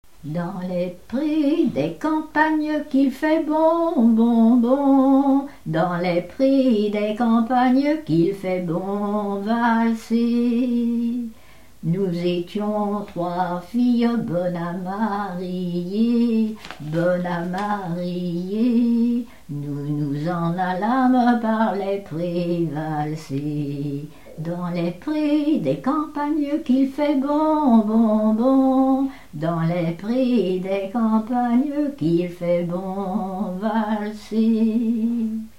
Mémoires et Patrimoines vivants - RaddO est une base de données d'archives iconographiques et sonores.
Genre laisse
Catégorie Pièce musicale inédite